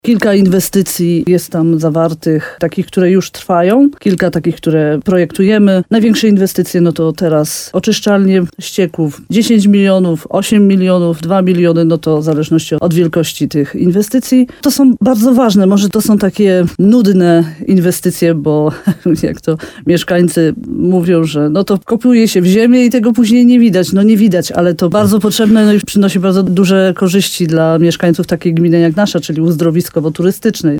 Jak mówi wójt Ewa Garbowska-Góra, tegoroczny budżet spiął się bardzo dobrze, jeśli chodzi planowane inwestycji z zewnętrznych dotacji i wkładu własnego.